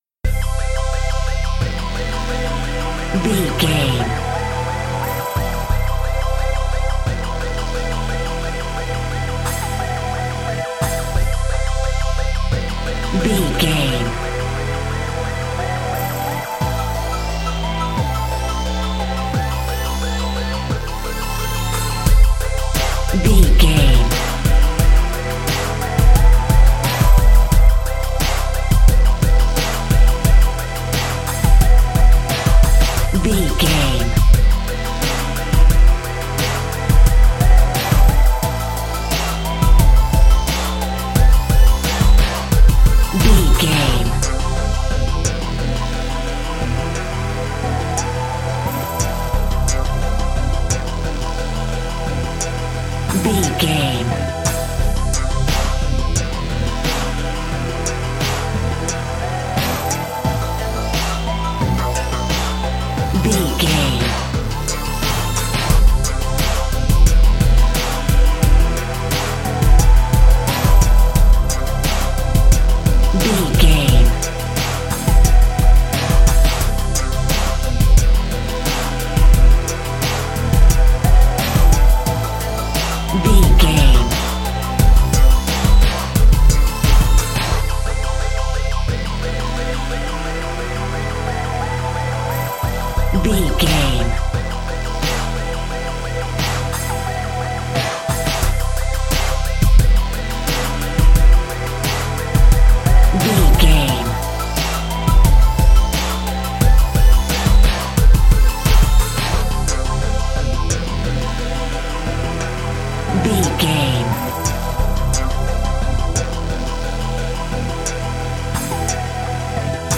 Aeolian/Minor
synthesiser
drum machine